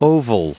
oval_en-us_recite_stardict.mp3